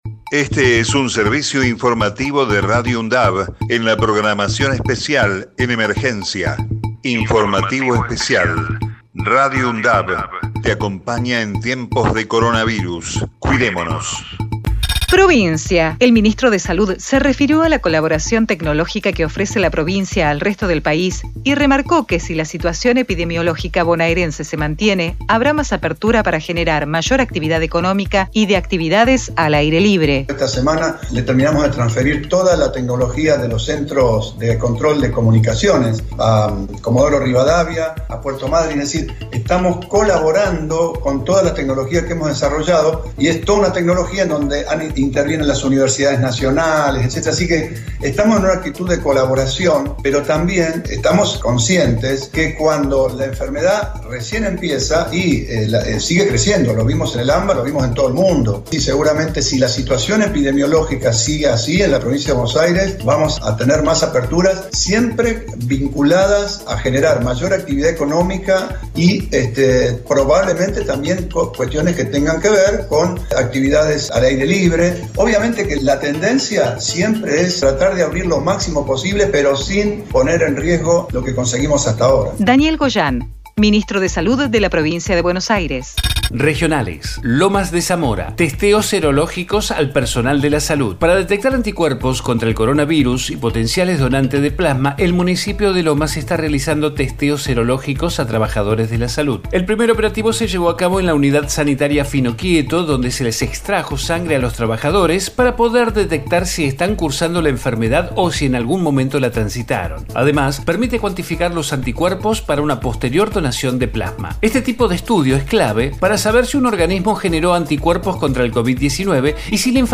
COVID-19 Informativo en emergencia 05 de octubre 2020 Texto de la nota: Este es un servicio informativo de Radio UNDAV en la programación especial en emergencia.